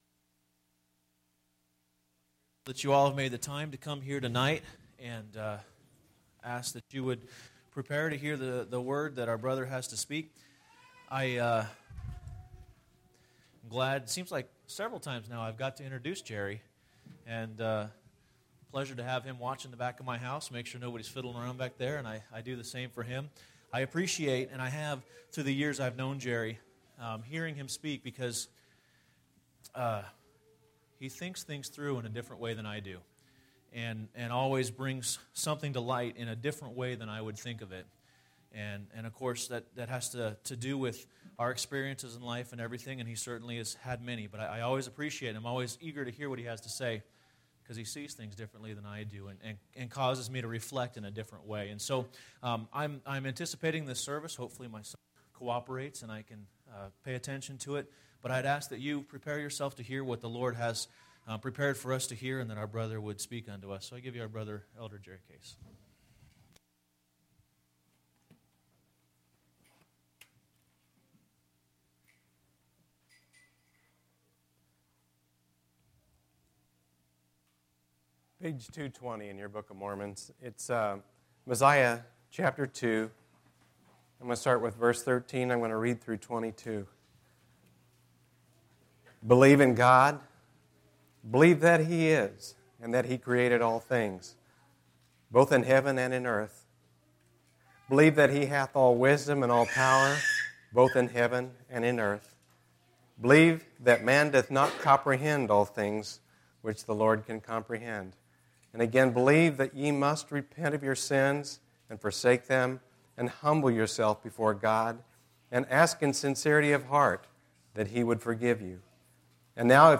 10/16/2011 Location: Phoenix Local Event